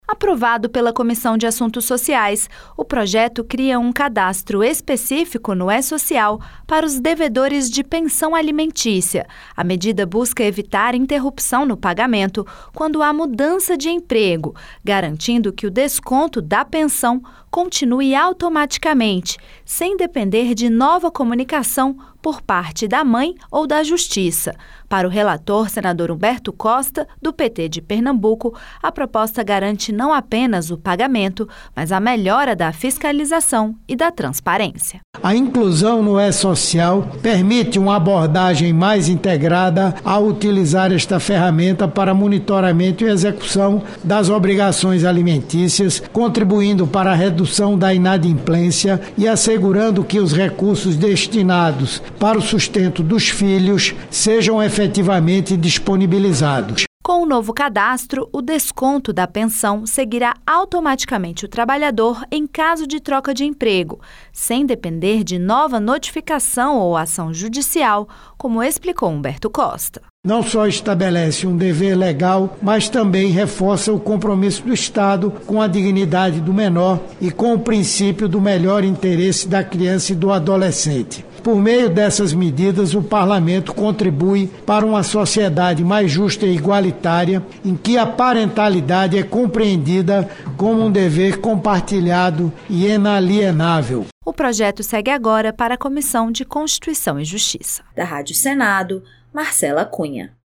Com a medida, o desconto da pensão será feito automaticamente na folha de pagamento, mesmo que o trabalhador mude de emprego. O relator da proposta, senador Humberto Costa (PT-PE), destacou que, atualmente, muitas crianças deixam de receber a pensão no período entre a demissão e o início do novo vínculo empregatício.